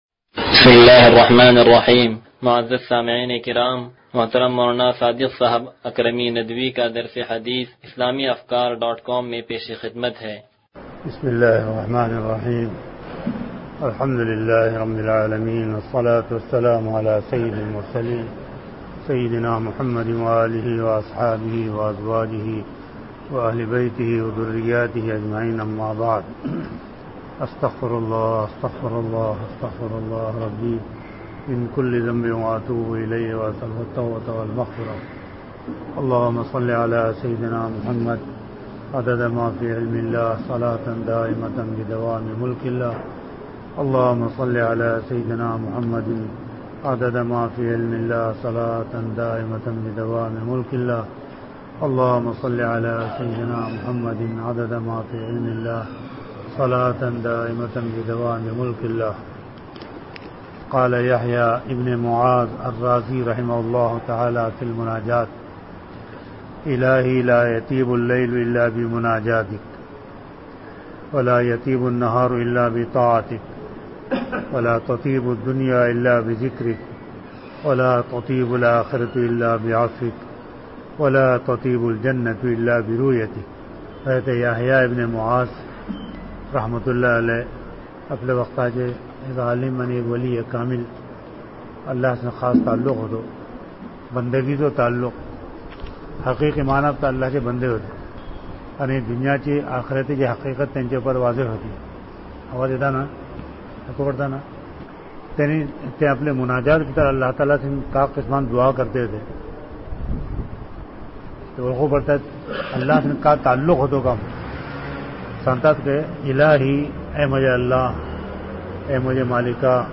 درس حدیث نمبر 0138
تنظیم مسجد